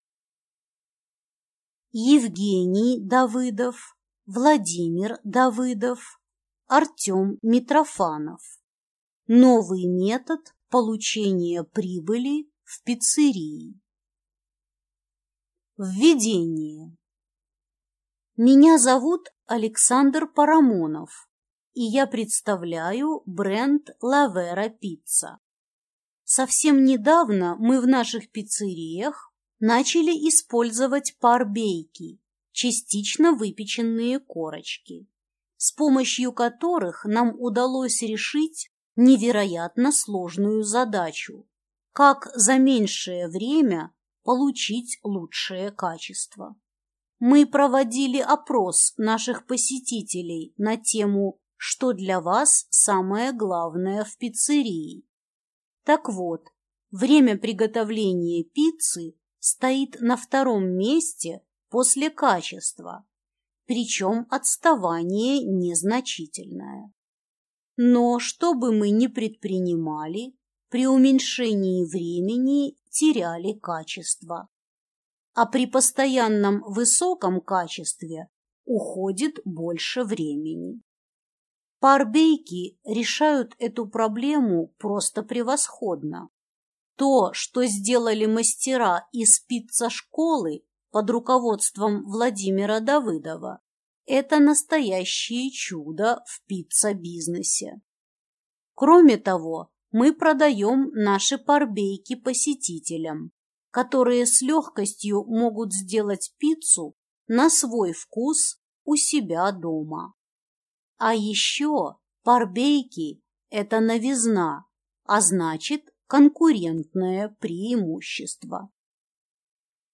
Аудиокнига Новый метод получения прибыли в пиццерии – частично выпеченная корочка (парбейк) | Библиотека аудиокниг